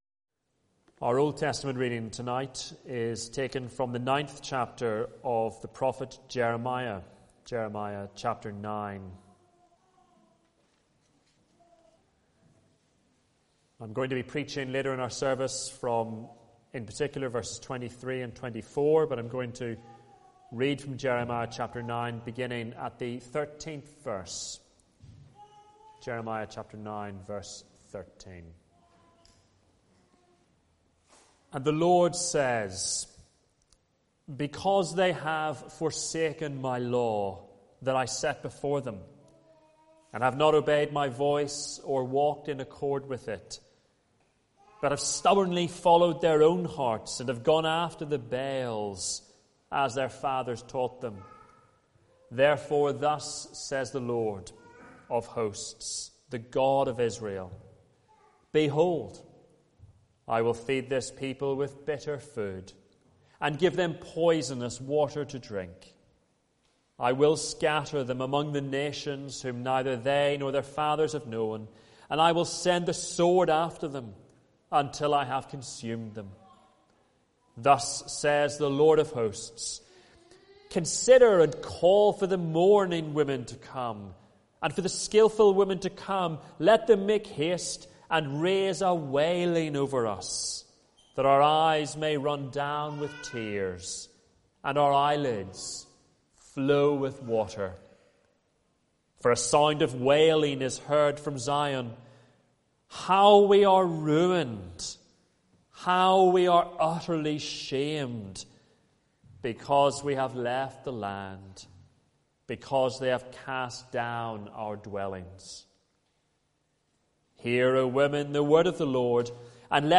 Sunday Evening Reading and Sermon Audio